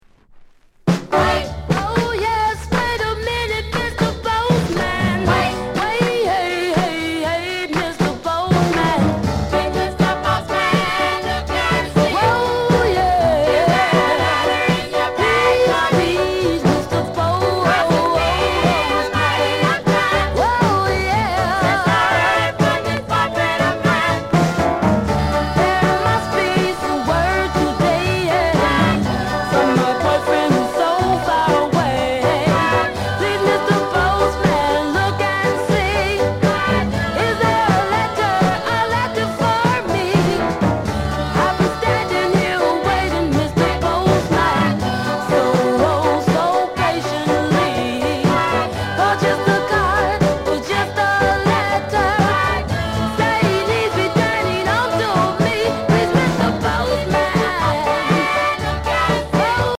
SOUND CONDITION VG